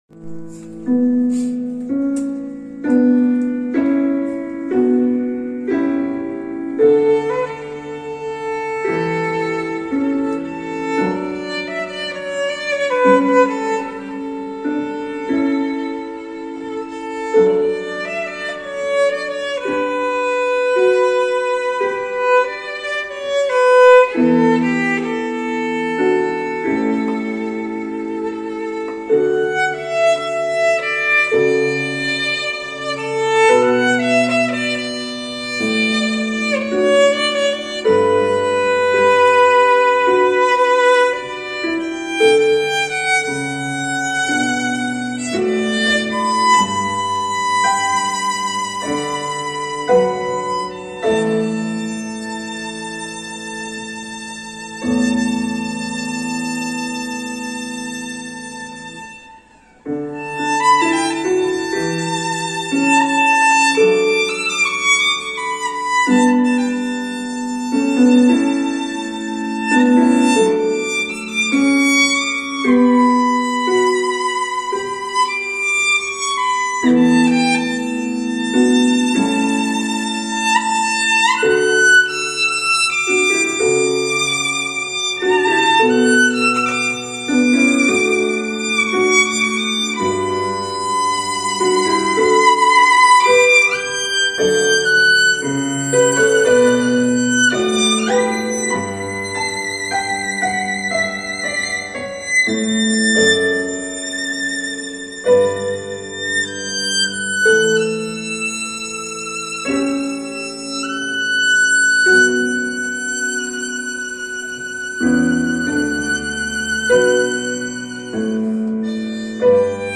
Violine Solo (mit Playback)